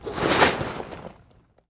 Index of /server/sound/weapons/hk45
draw.wav